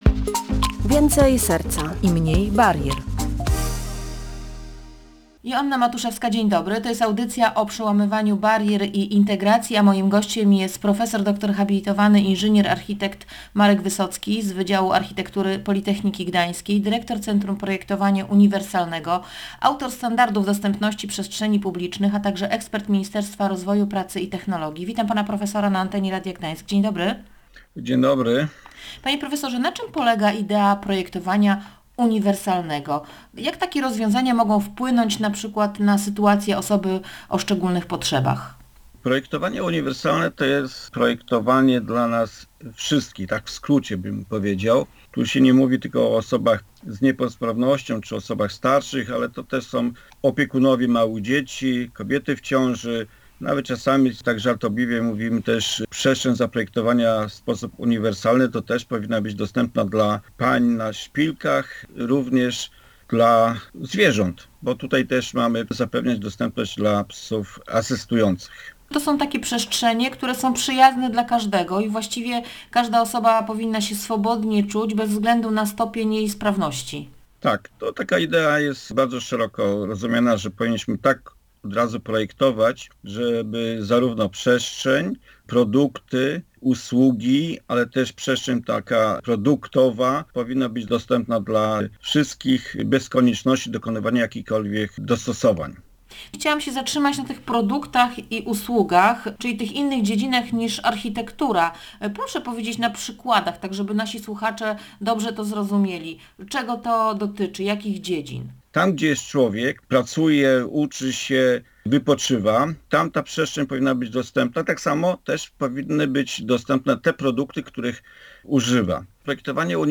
Architekt: Uniwersalne projektowanie zapewnia przestrzenie, których nie trzeba dostosowywać do niczyich potrzeb.